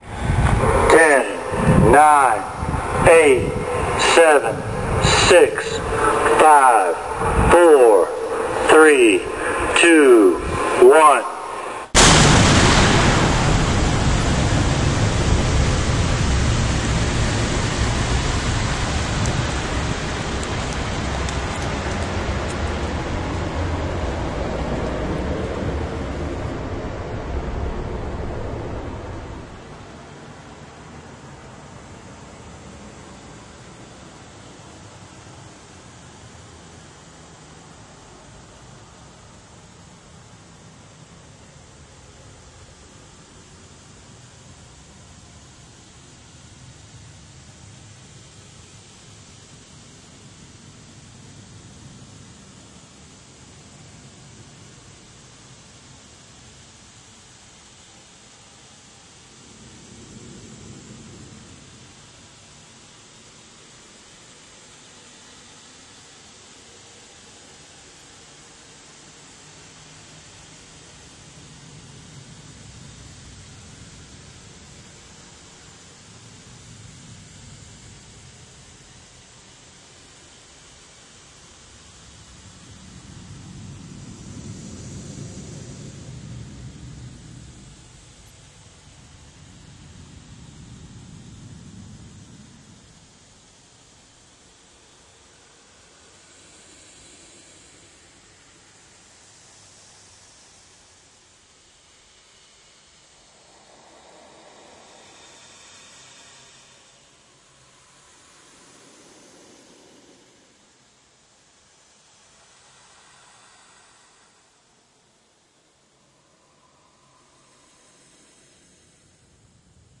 Звук обратного отсчета, когда все ждут начало Нового года, но вместо праздника грянет ядерный взрыв (а вы думали куранты будут?)